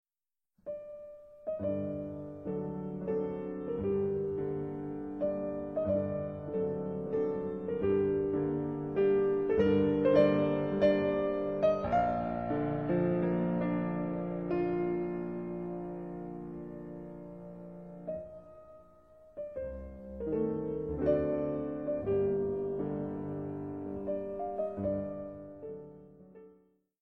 mp3Schumann, Clara, Soirées musicales, Op. 6, No. 3 Mazurka, Moderato, mm.1-8